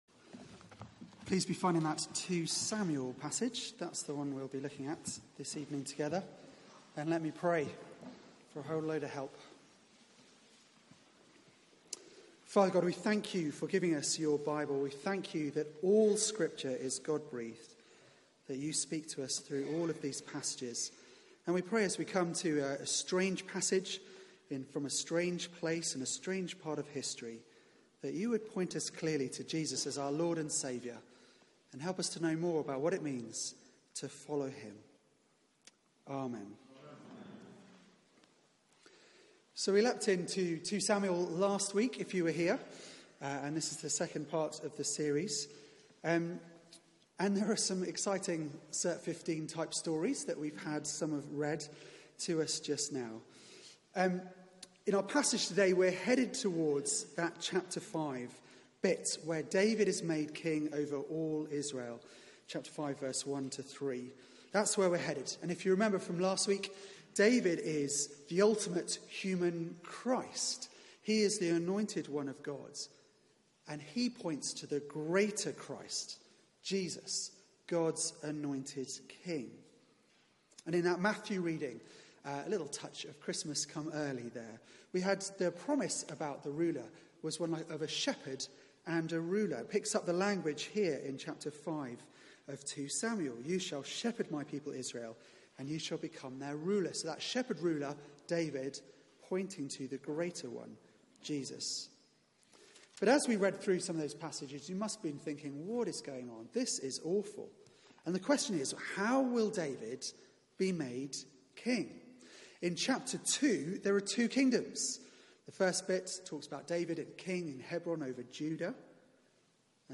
Media for 6:30pm Service on Sun 25th Nov 2018
Series: King David's Greater King Theme: The making of a king Sermon